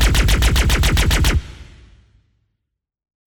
fire3.wav